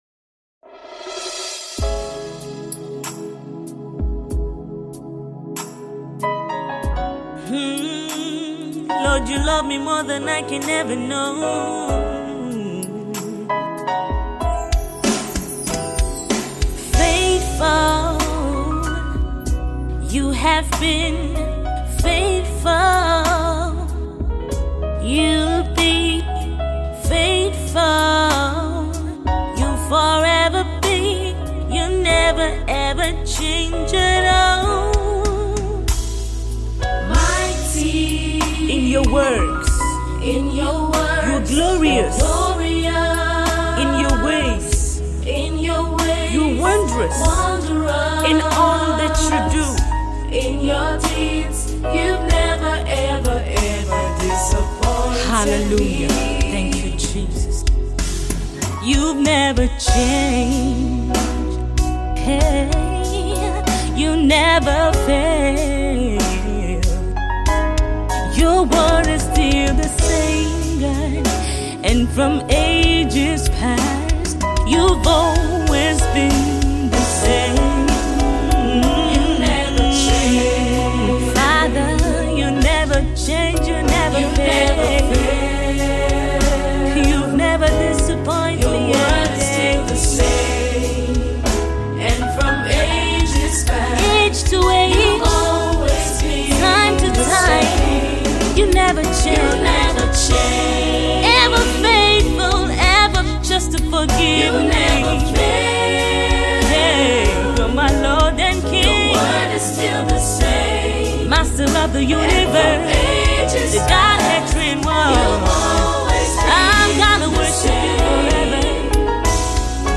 soul lifting song
worship enlightened piece
gospel singer